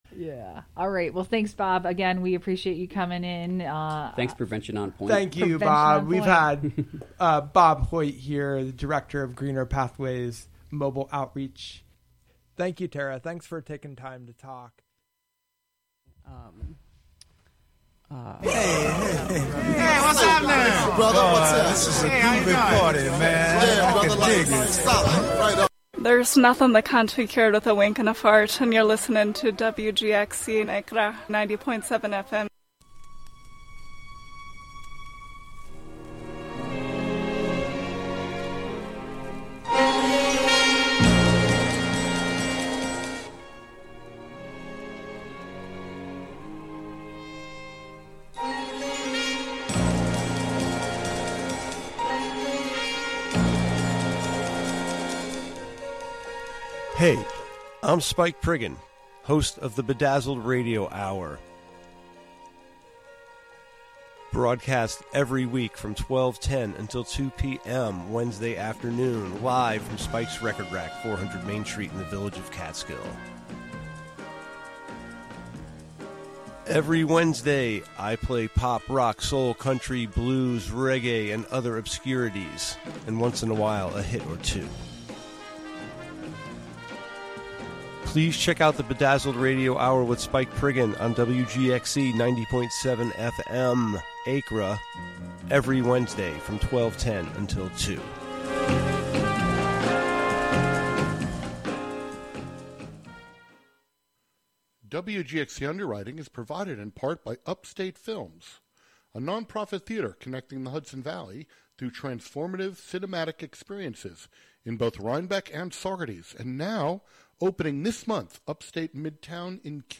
interviews with community leaders and local personalities